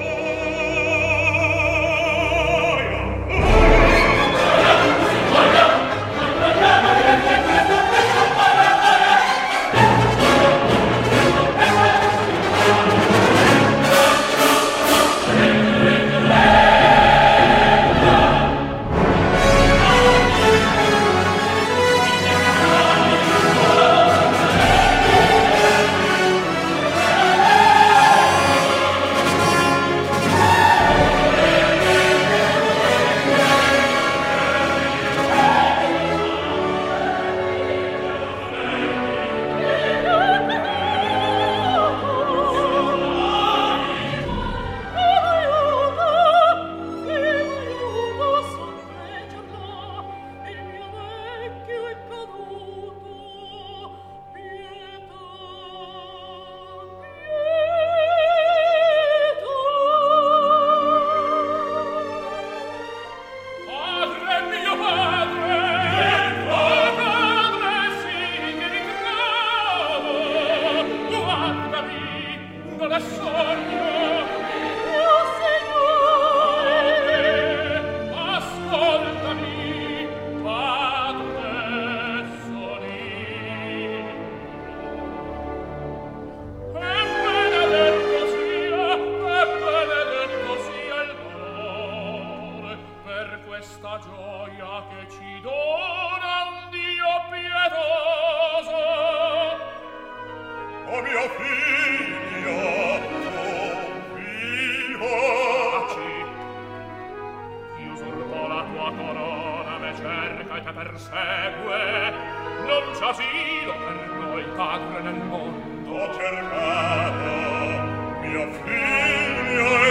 (Un Mandarino, Chorus)